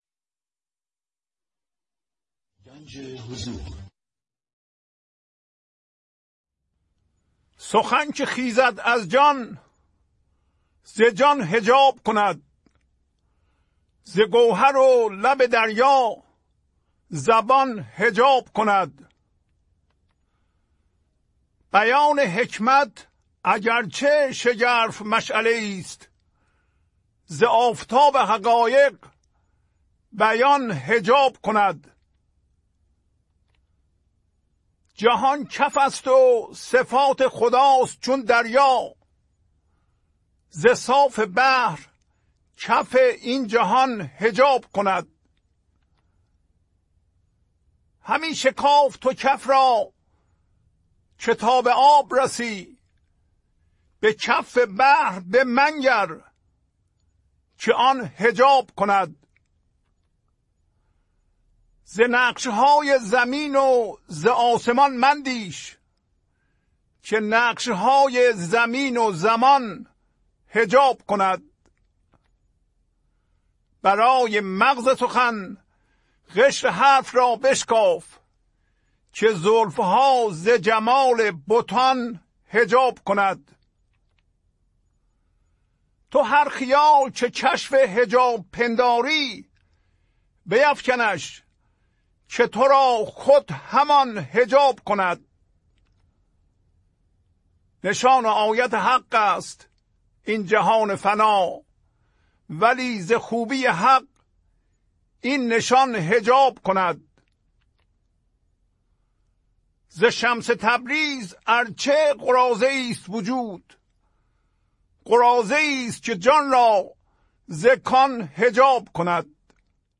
خوانش تمام ابیات این برنامه - فایل صوتی
1021-Poems-Voice.mp3